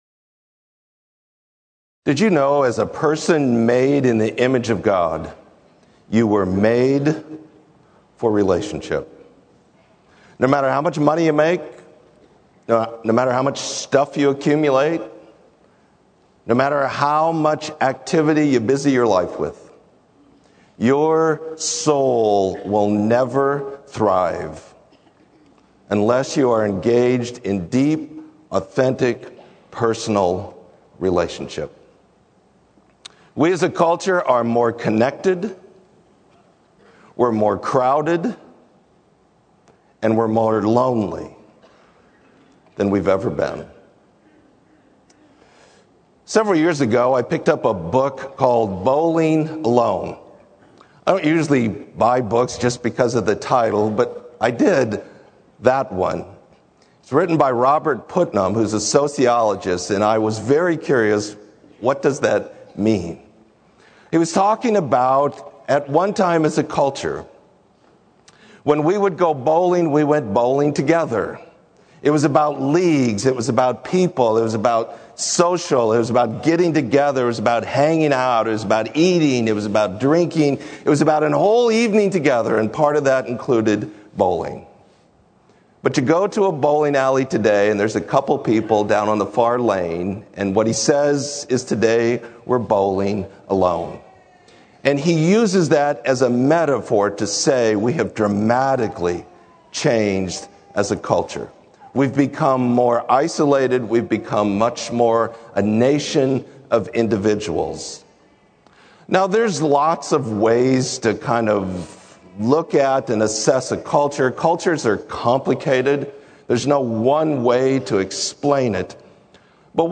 Sermon - Lincoln Berean